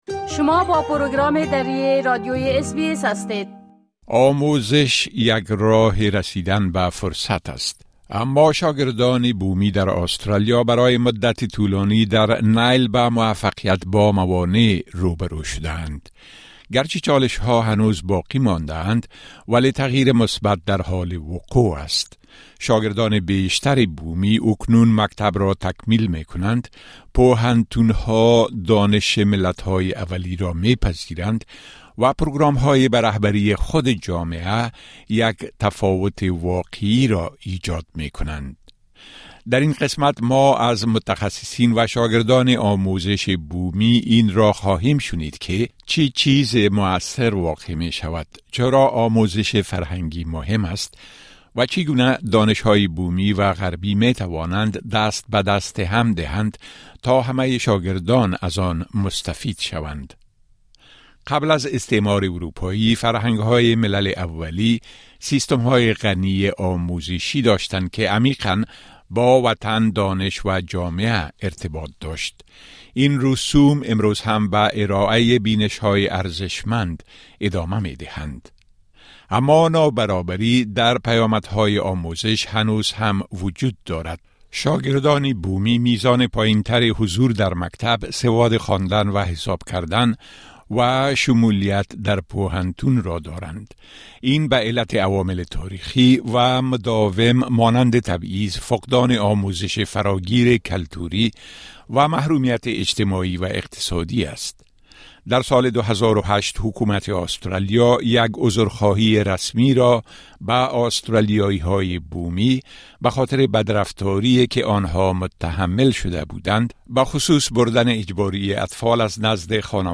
در این قسمت روزنه‌ای به آسترالیا، ما از متخصصین و شاگردان آموزش بومی اين‌را خواهيم شنيد که چه چیزی مؤثر واقع مى شود، چرا آموزش فرهنگى مهم است، و چگونه دانش بومی و غربی می‌توانند دست بدست هم دهند تا همه شاگردان از آن مستفيد شوند.